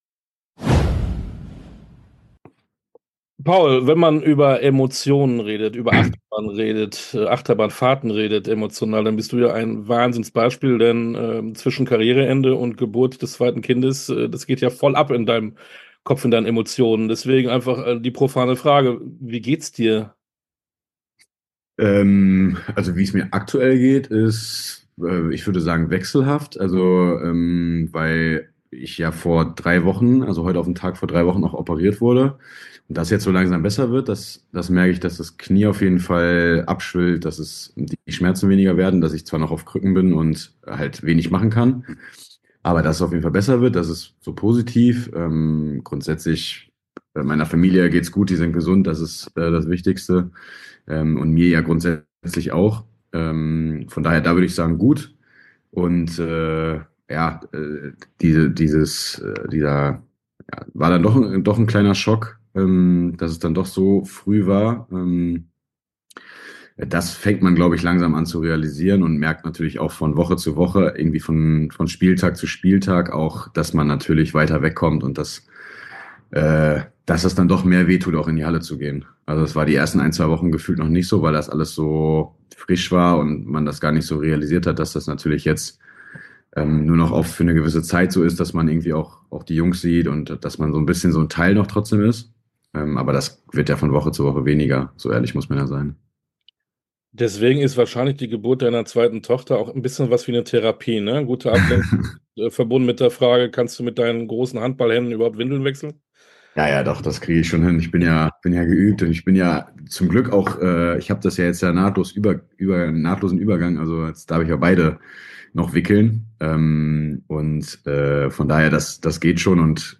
Sportstunde - Interview komplett Paul Drux Handball ehem Spieler Füchse Berlin ~ Sportstunde - Interviews in voller Länge Podcast